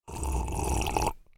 دانلود آهنگ آب 11 از افکت صوتی طبیعت و محیط
دانلود صدای آب 11 از ساعد نیوز با لینک مستقیم و کیفیت بالا
جلوه های صوتی